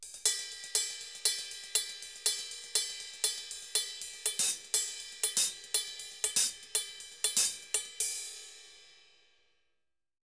Ride_Baion 120_1.wav